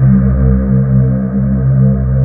Index of /90_sSampleCDs/USB Soundscan vol.28 - Choir Acoustic & Synth [AKAI] 1CD/Partition D/01-OUAHOUAH